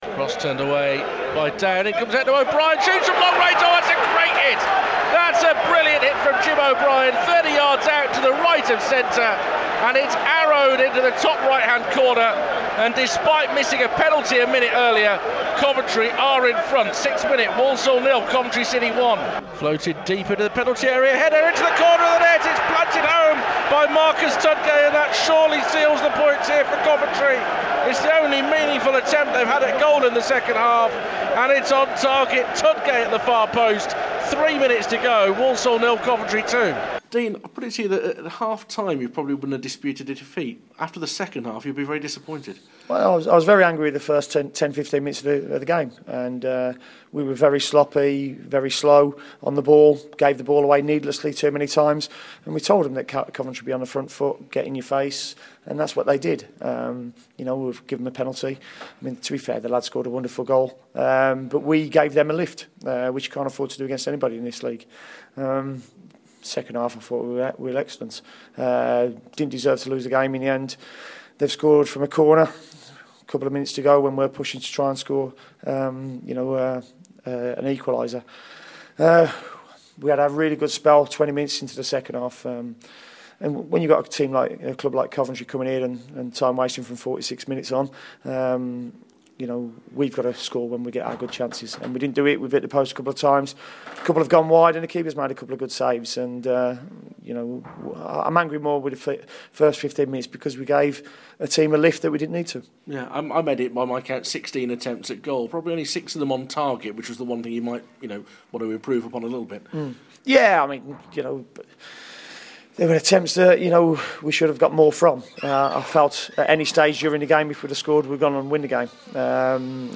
desribes the action and talks to Dean Smith.